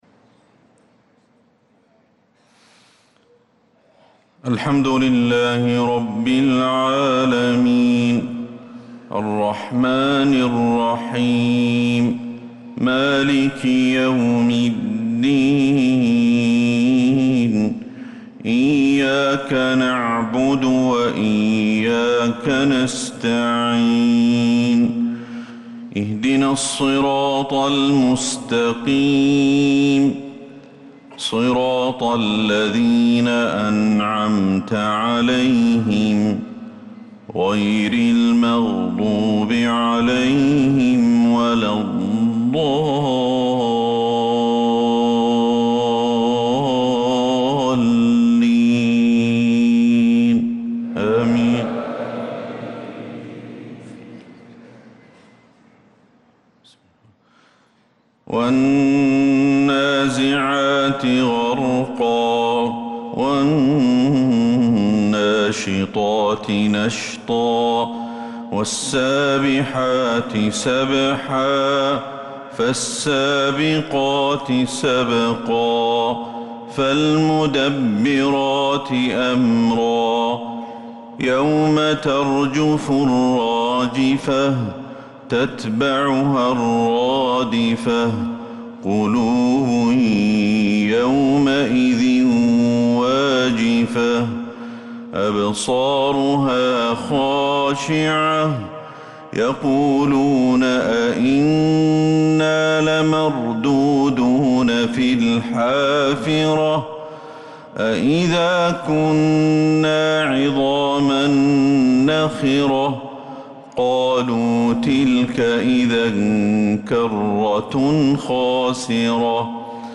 صلاة الفجر للقارئ أحمد الحذيفي 5 ذو القعدة 1445 هـ
تِلَاوَات الْحَرَمَيْن .